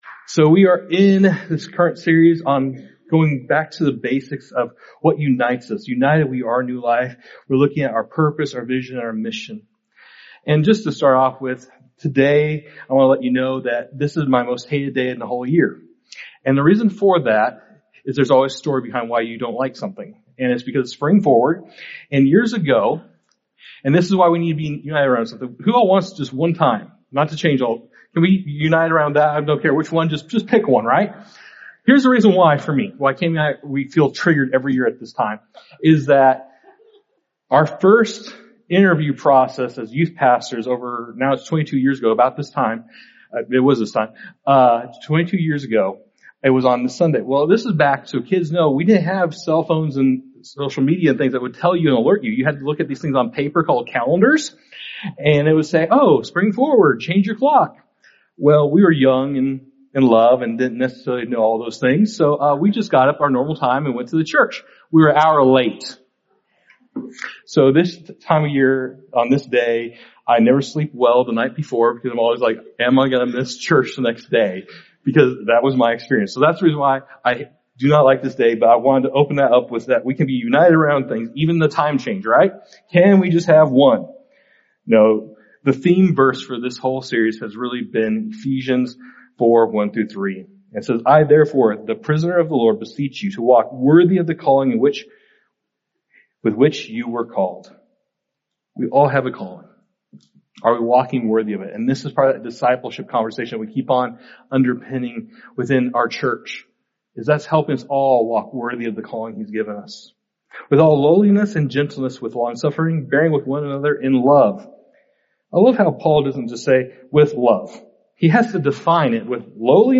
God calls us to live sent—to go and share His love with the world. In 2 Corinthians 5:18-20, we are reminded that we are His ambassadors, carrying the message of reconciliation. This sermon, "United: Go," challenges us to be intentional in every interaction—at home, at work, and in our communities.